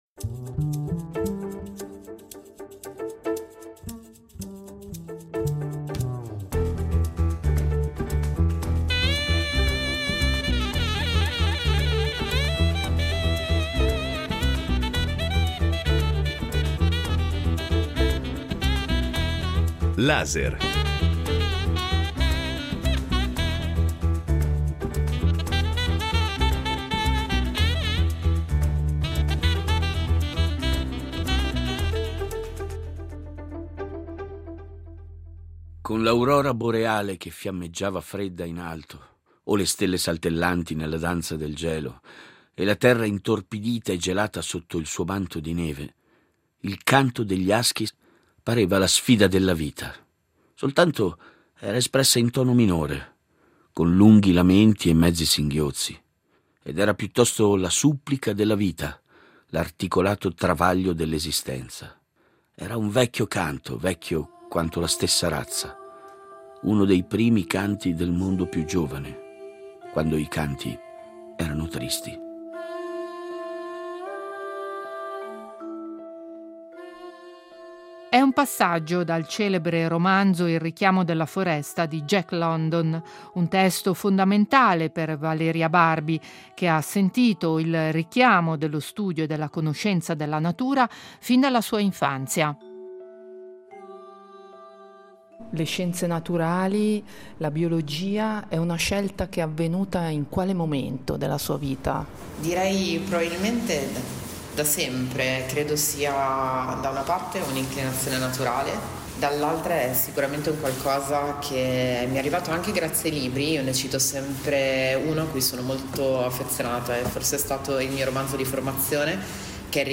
Incontro